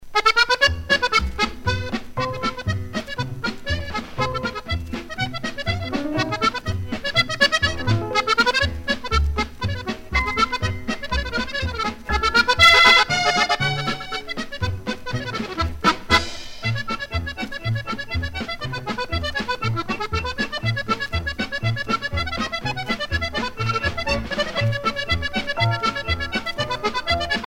danse : fox-trot
Pièce musicale éditée